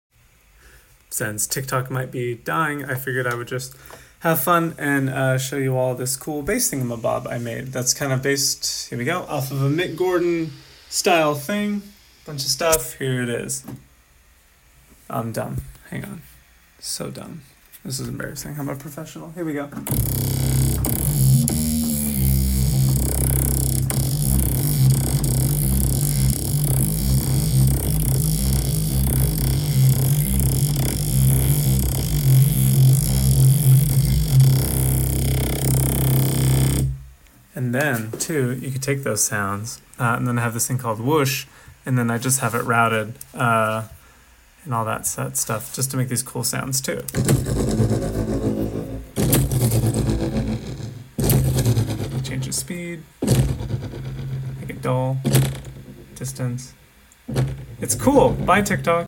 Neat bass sound I made sound effects free download
Neat bass sound I made and bonus sound design hits.